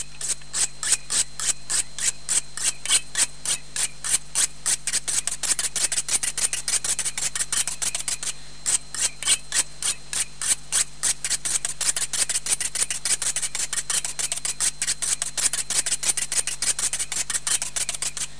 knives.mp3